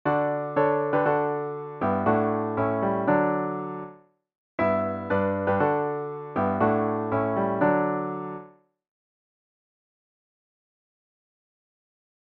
(If this doesn't make sense to you, imagine starting "Joy to the World" one pitch higher, but still in the same key.